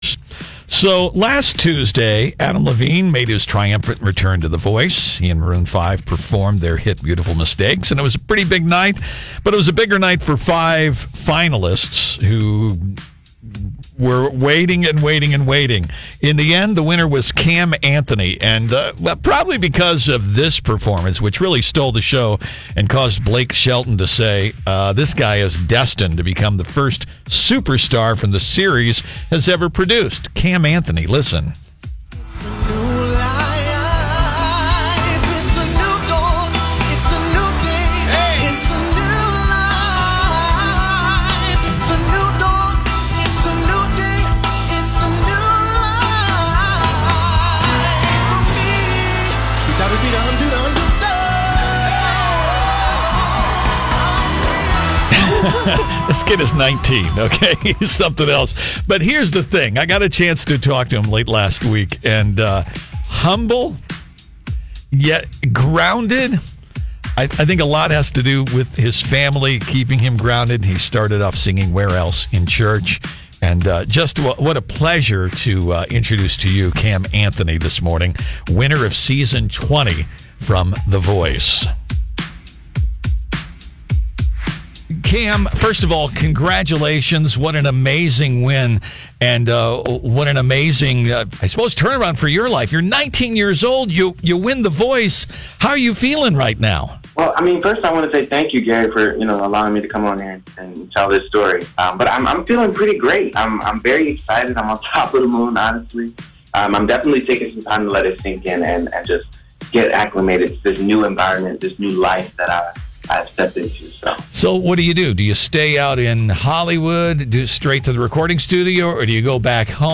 Voice Winner Cam Anthony was a guest on the Mix Morning Show Tuesday Morning.